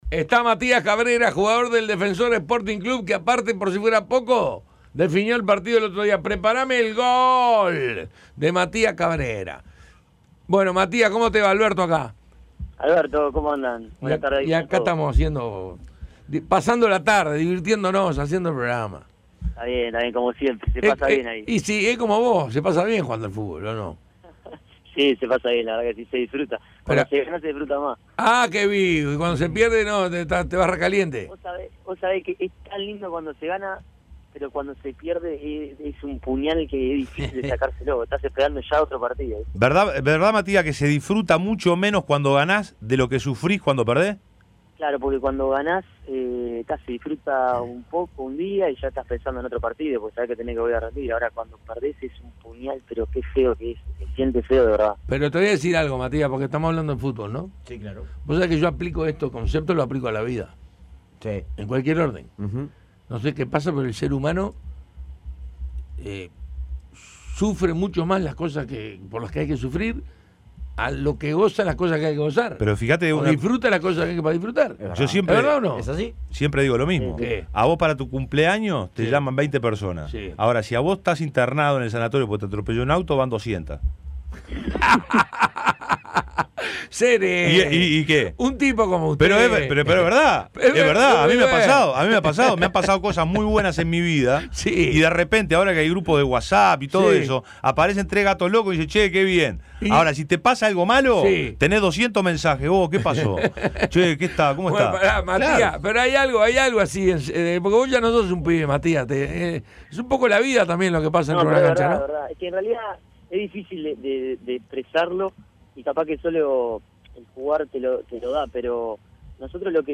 Entrevista completa.